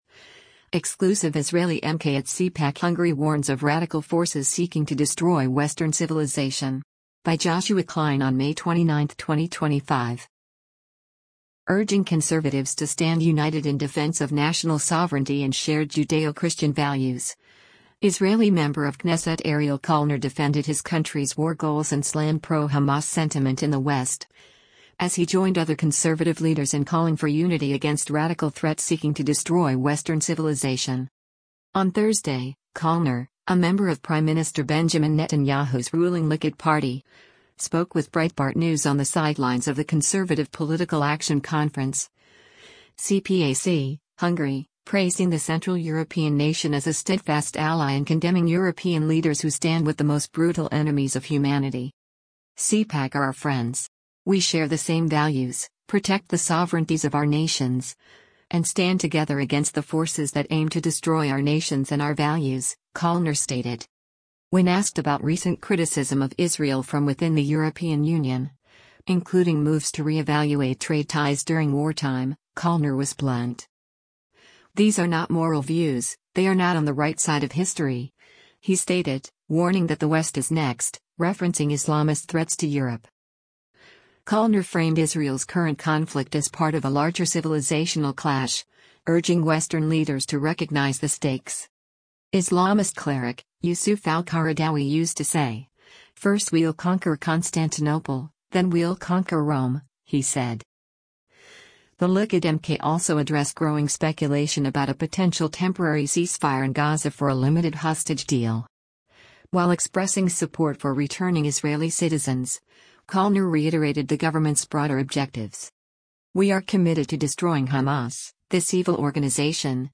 On Thursday, Kallner — a member of Prime Minister Benjamin Netanyahu’s ruling Likud Party — spoke with Breitbart News on the sidelines of the Conservative Political Action Conference (CPAC) Hungary, praising the Central European nation as a steadfast ally and condemning European leaders who “stand with the most brutal enemies of humanity.”